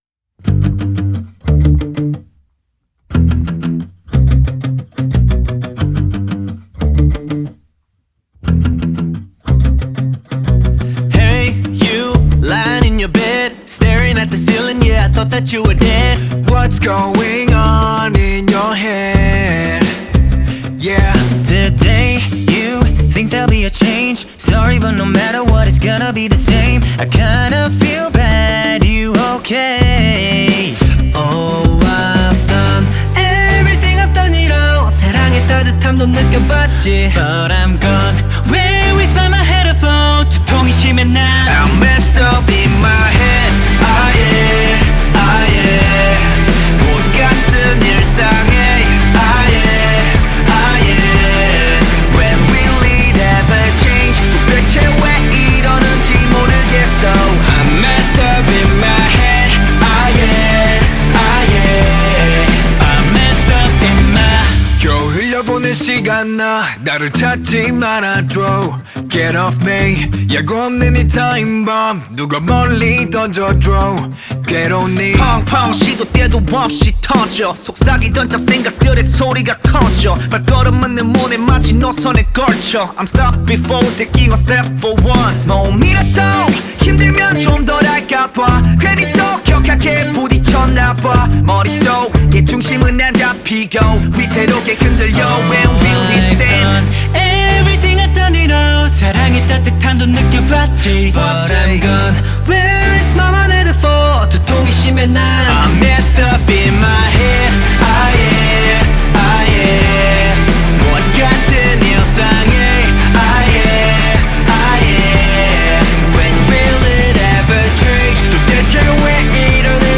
Корейские песни